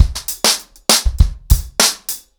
BellAir-A-100BPM__1.7.wav